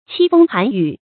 凄風寒雨 注音： ㄑㄧ ㄈㄥ ㄏㄢˊ ㄧㄩˇ 讀音讀法： 意思解釋： 見「凄風苦雨」。